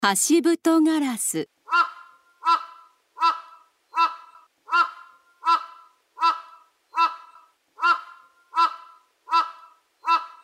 ハシブトガラス
【鳴き声】ハシブトガラスは澄んだ声で「アーアーアー」「アワーアワー」「カァカァ」と鳴く。
ハシブトガラスの鳴き声（音楽：172KB）